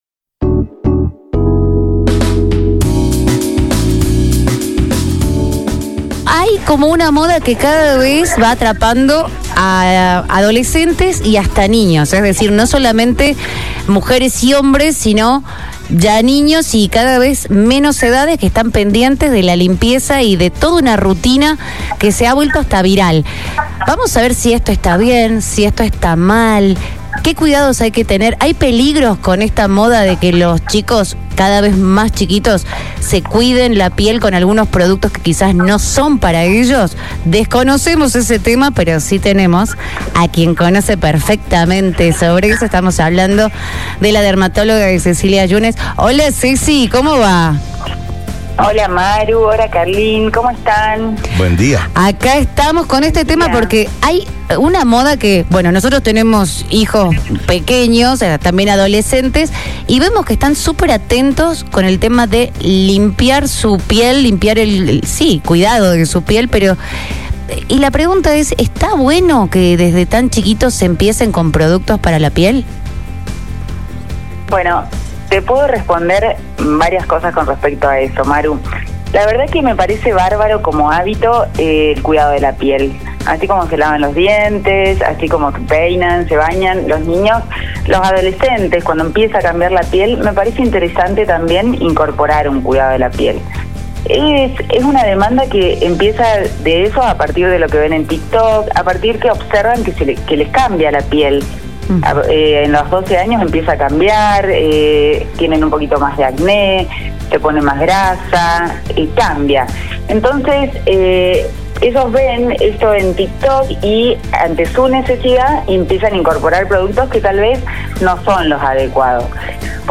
Por esto dialogamos con la médica dermatóloga